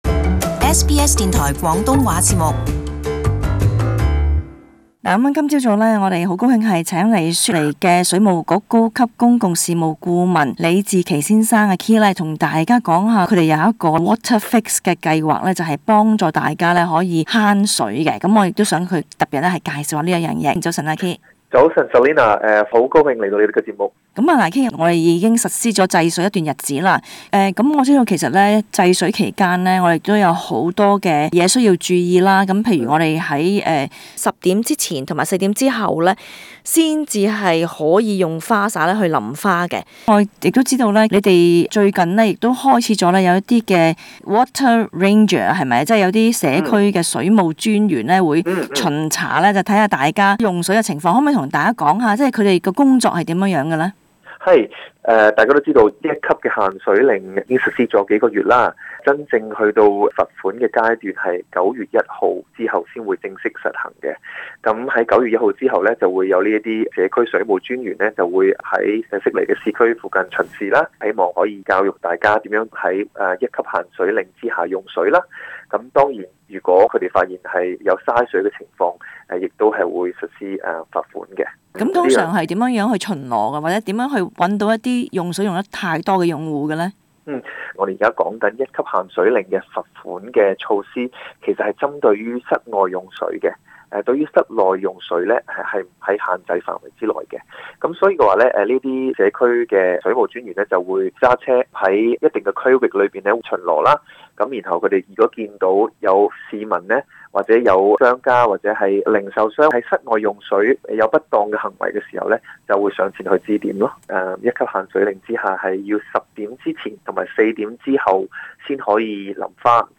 【社區專訪】雪梨水務局Waterfix計劃助你節省用水